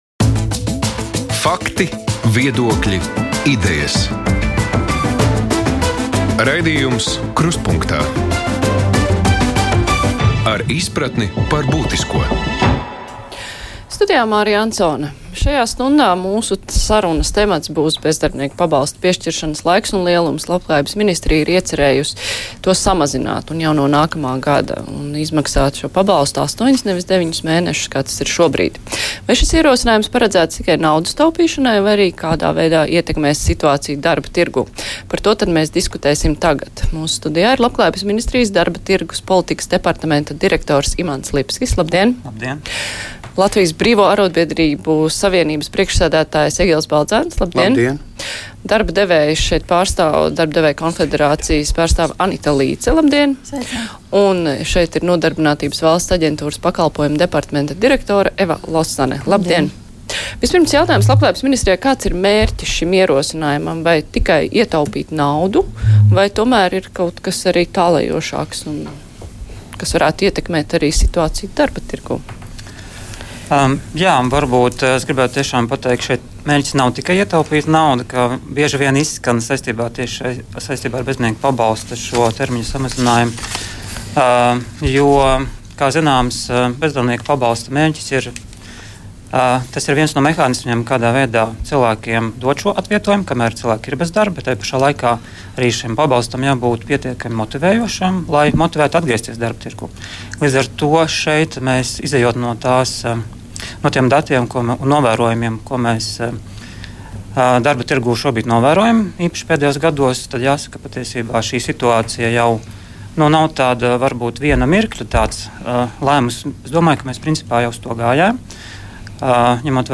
Labklājības ministrija iecerējusi samazināt bezdarbnieka pabalsta piešķiršanas laiku un lielumu. Diskusija raidījumā "Krustpunktā".